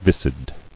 [vis·cid]